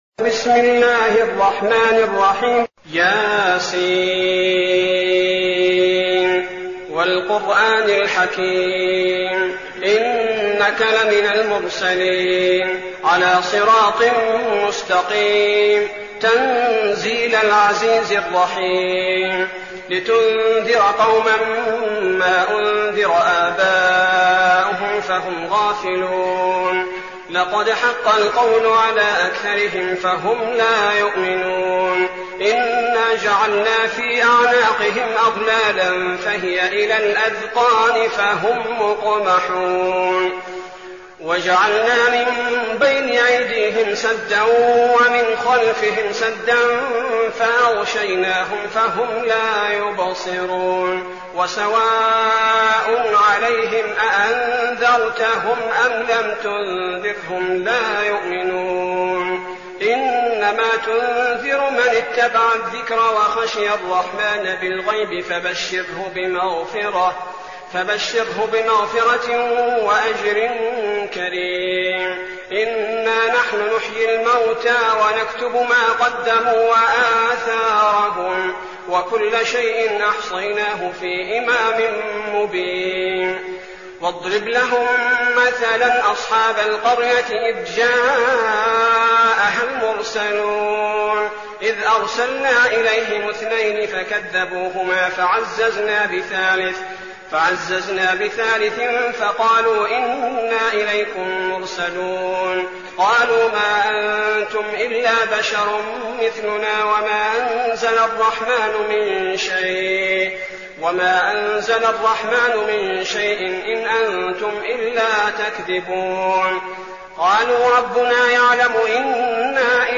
المكان: المسجد النبوي الشيخ: فضيلة الشيخ عبدالباري الثبيتي فضيلة الشيخ عبدالباري الثبيتي يس